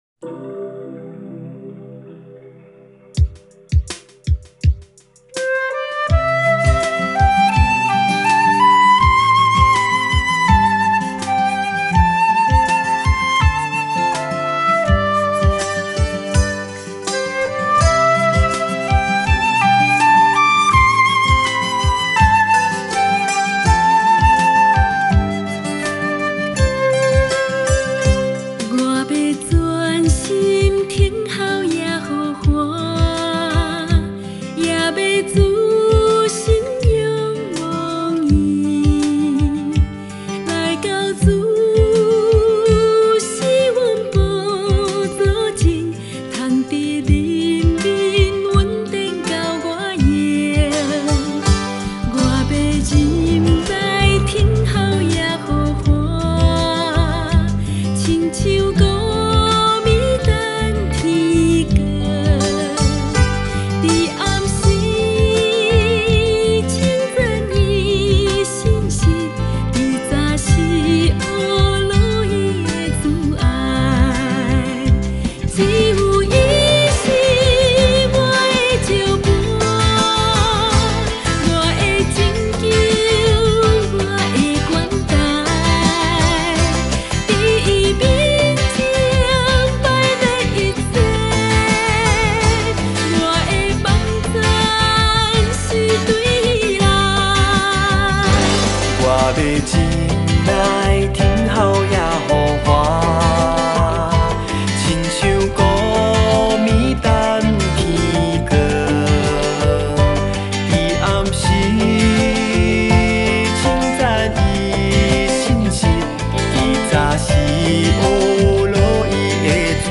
mp3 原唱音樂
flute